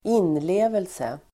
Uttal: [²'in:le:velse]